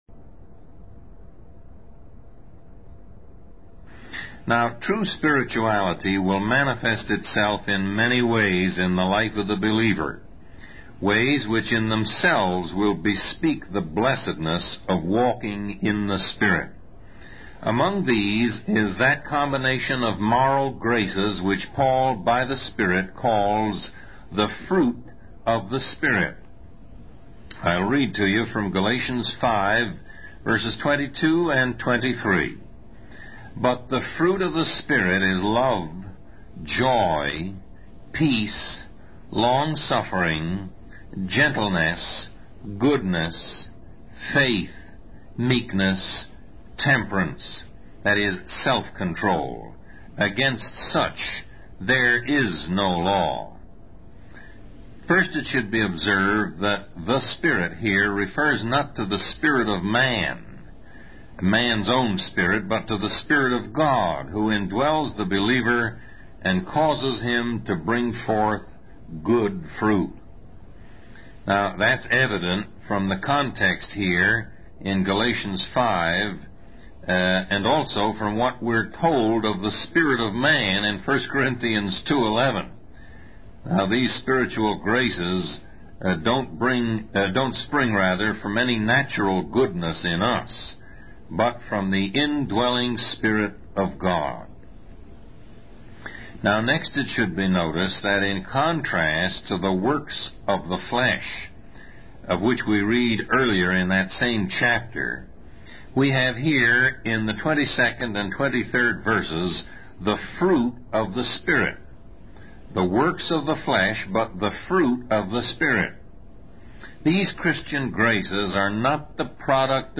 Lesson 16: Manifestations of True Spirituality